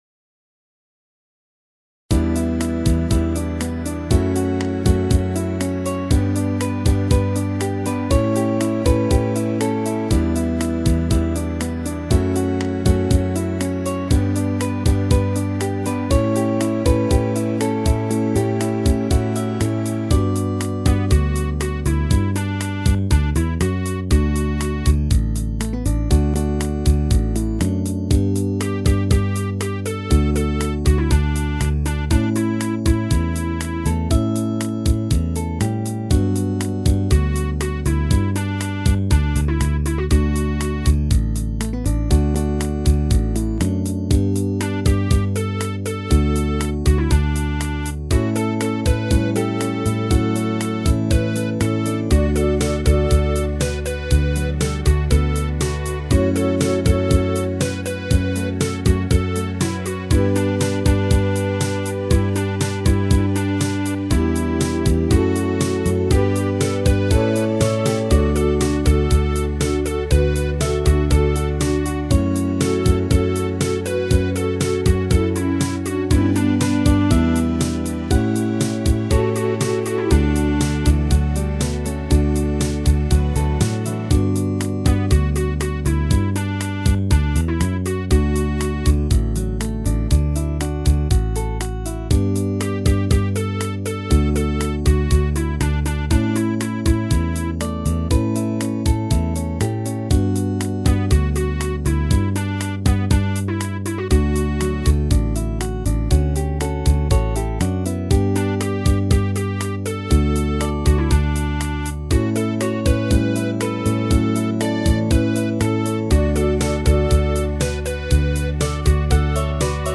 スライドギターだのなんだの面倒なのでGはナイロンギターに統一。
その方がメロディラインの邪魔をせずガチャガチャしない。